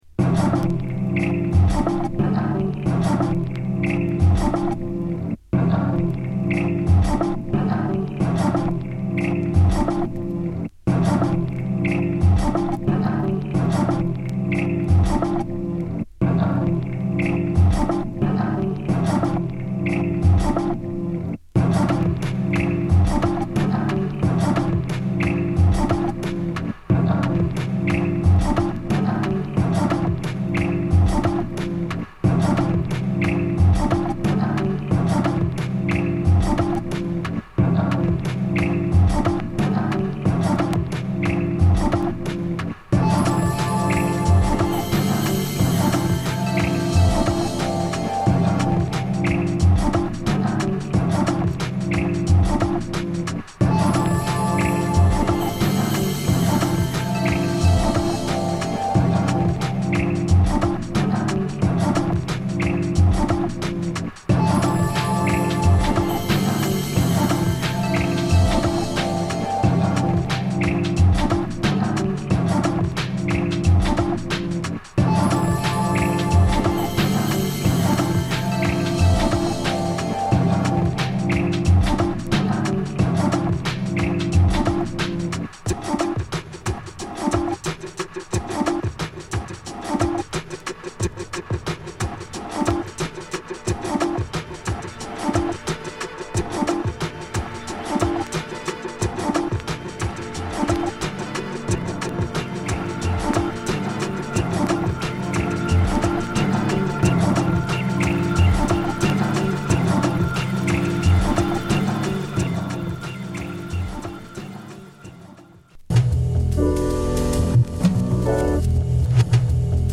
煙たい上音にきらびやかな電子音が散りばめられたAmbient Breakbeats